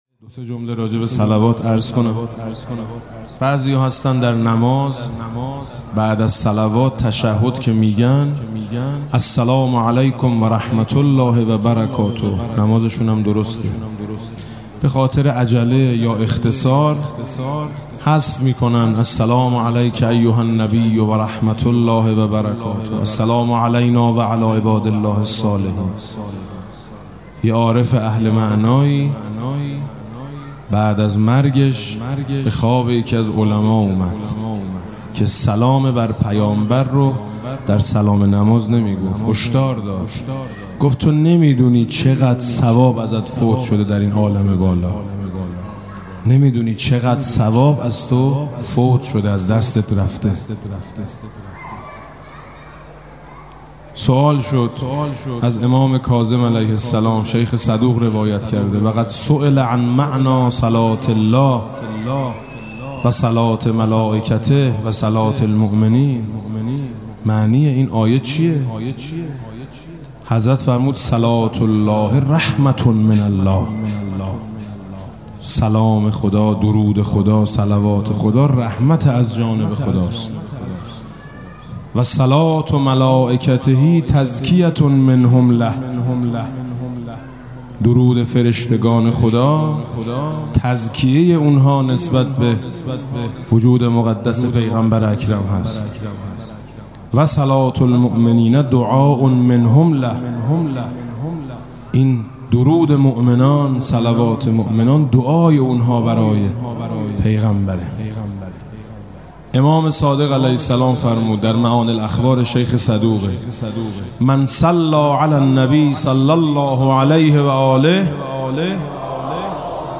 [حرم حضرت فاطمه معصومه (س)]
با نوای: حاج میثم مطیعی